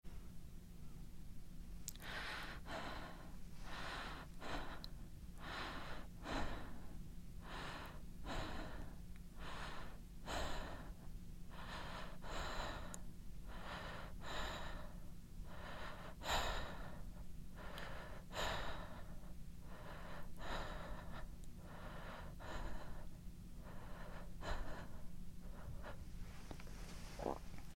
Звуки женского дыхания
На этой странице собраны звуки женского дыхания в разных тембрах и ритмах: от спокойного до учащенного.
Шепот нежного дыхания девушки